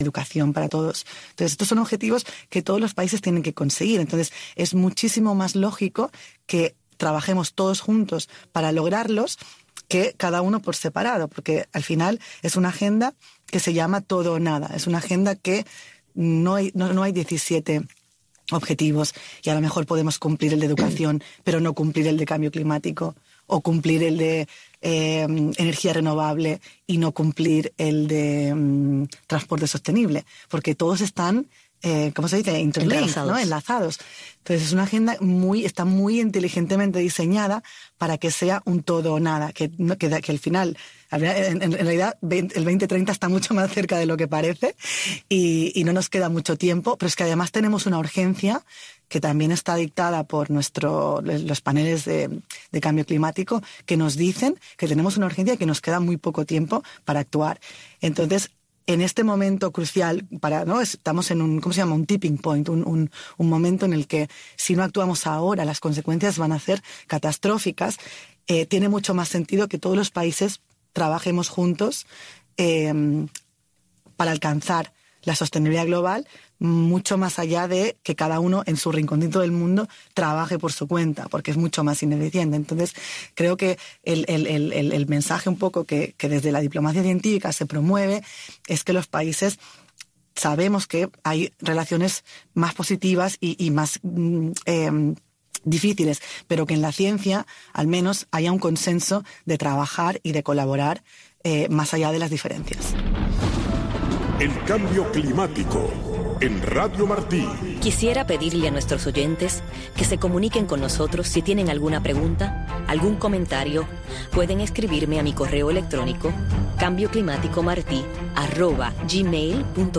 Radio Martí ha reunido a científicos de primer nivel de diferentes partes del mundo: astrónomos de la NASA, biólogos, doctores en Meteorología, expertos en Biodiversidad, entre otros